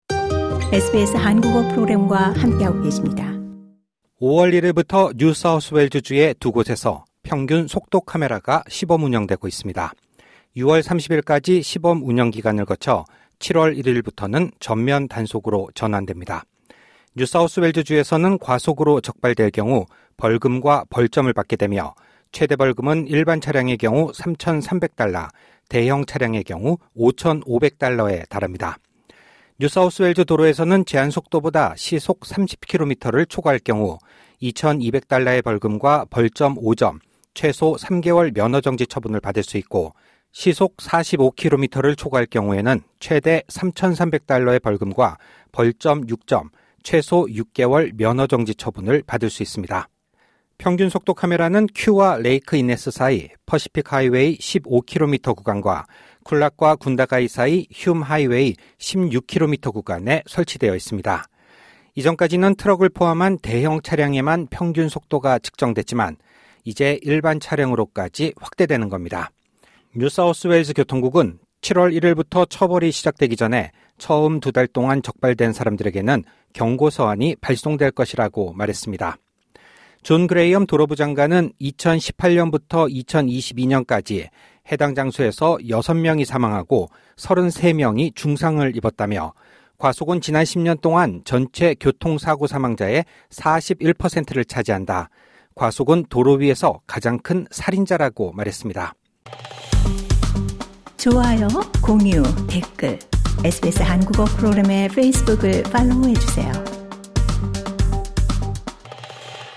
LISTEN TO 오늘부터 ‘한국 운전 면허증 → 호주 면허증’ 전환 불가 SBS Korean 01:29 Korean 상단의 오디오를 재생하시면 뉴스를 들으실 수 있습니다.